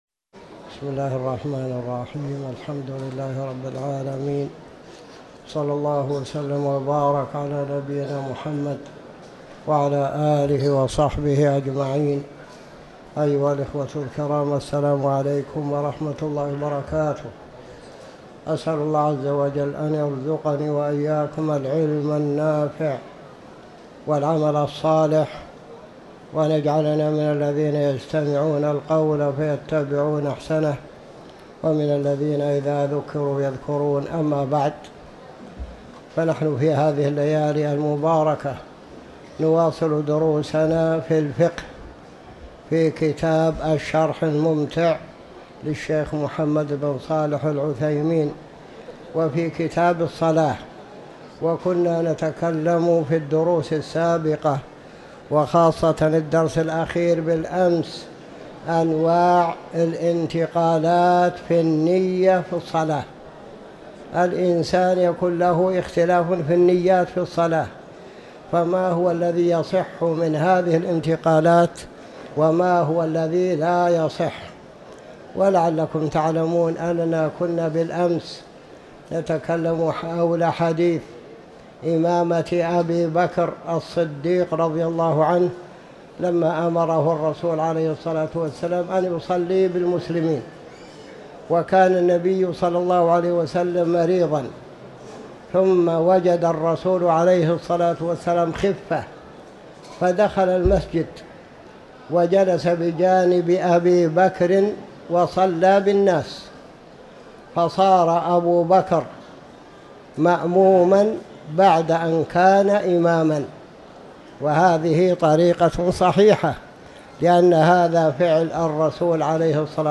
تاريخ النشر ٢٢ شوال ١٤٤٠ هـ المكان: المسجد الحرام الشيخ